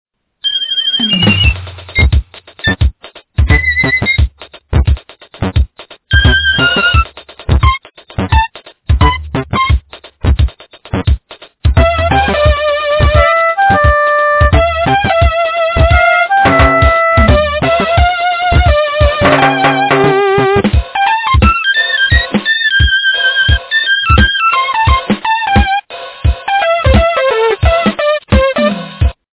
- фильмы, мультфильмы и телепередачи